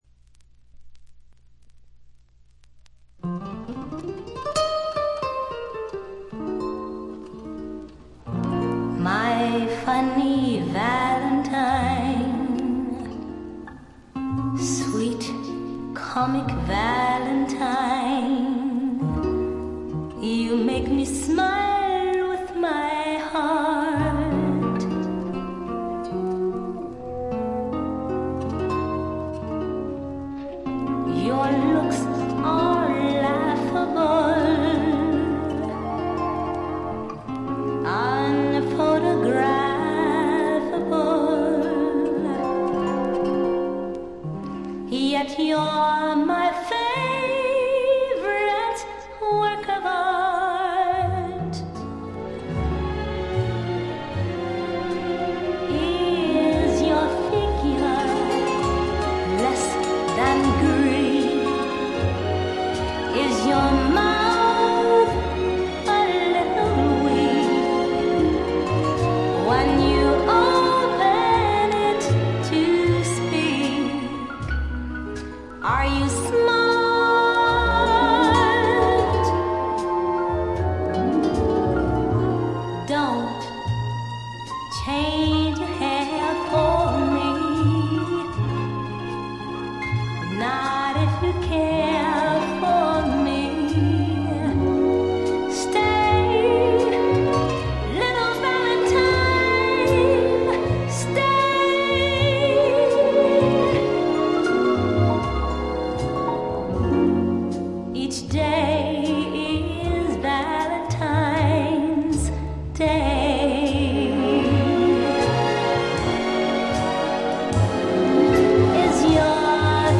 ほとんどノイズ感無し。
モノプレス。
試聴曲は現品からの取り込み音源です。